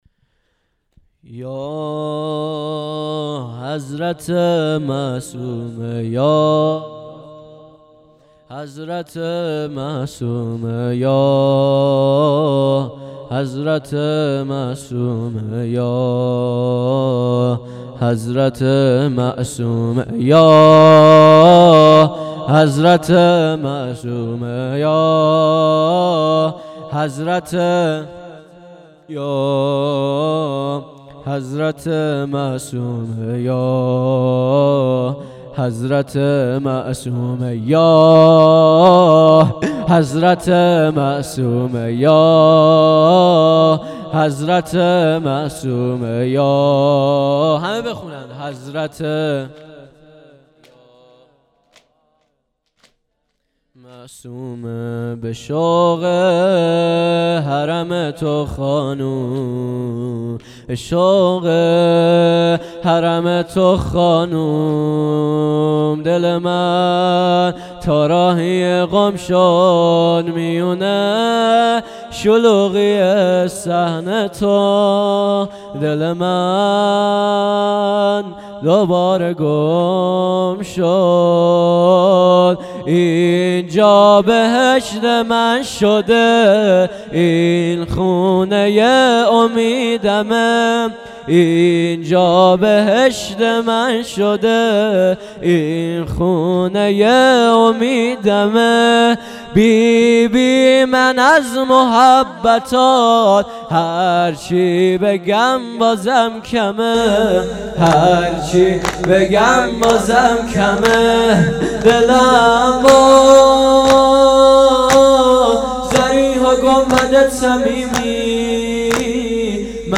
مسجد امام موسی بن جعفر علیه السلام | 28 آذرماه 1397